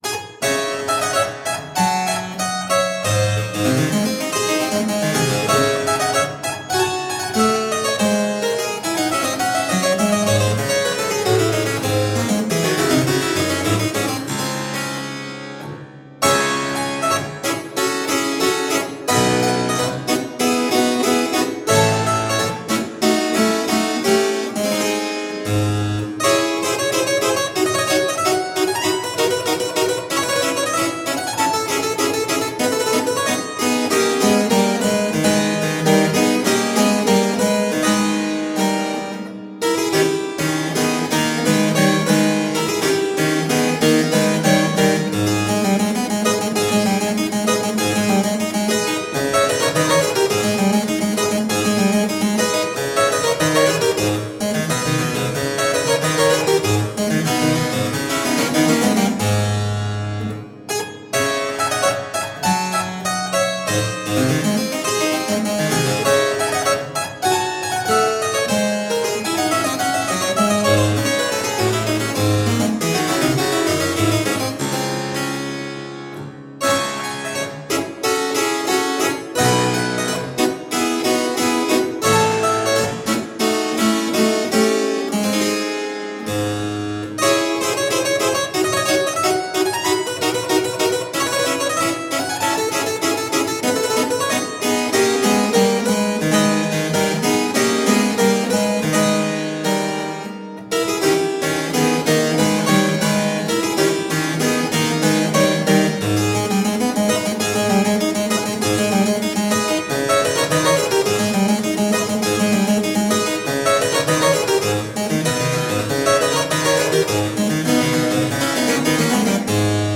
Delicious harpsichord rarities.
Classical, Classical Period, Instrumental, Harpsichord